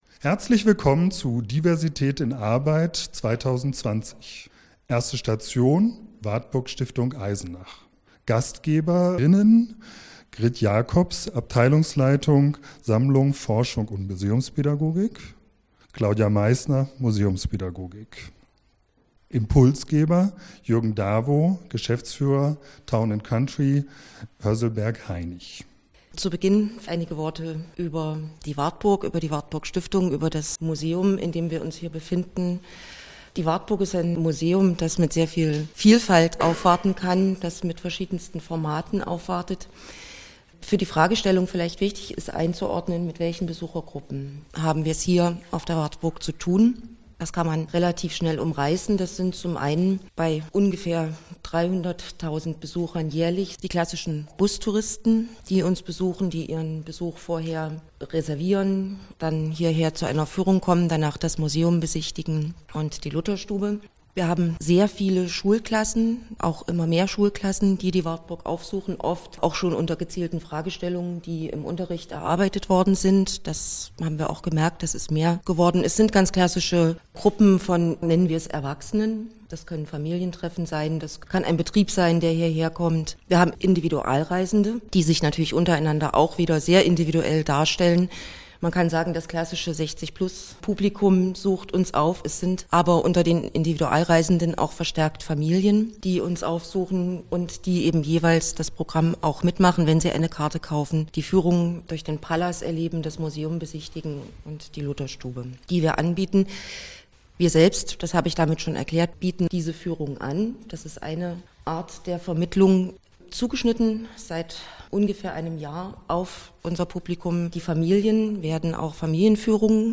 Erste Veranstaltung mit der Wartburg-Stiftung, Eisenach
DiA-Eisenach-Audio-Recording-Wartburg-Stiftung.mp3